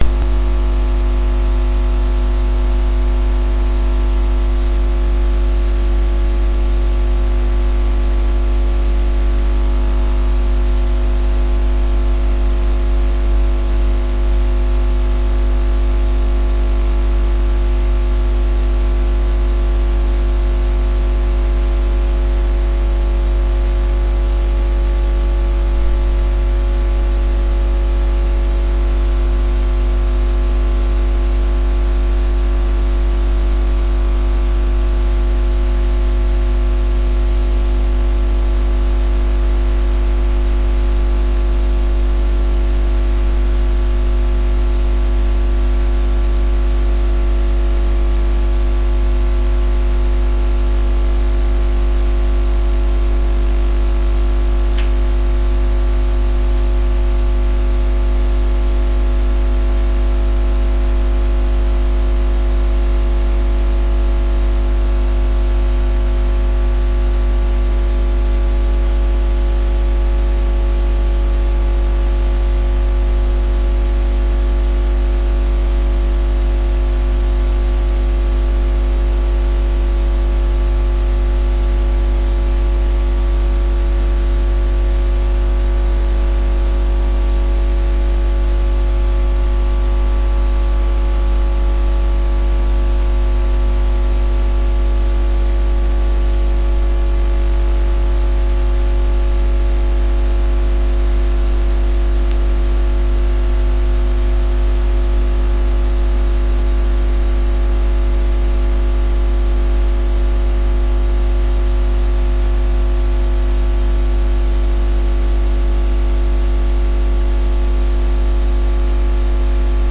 火灾 大规模无裂缝
描述：强烈的火焰声音，没有木材开裂;像破裂的管道，火箭助推器，火焰喷射器，大型喷火枪，等离子/能量束/钻头等。
Tag: 助推器 火焰 气体 火炬 燃烧 火箭